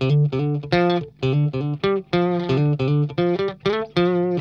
RARE RIFF LO.wav